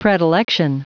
Prononciation du mot predilection en anglais (fichier audio)
Prononciation du mot : predilection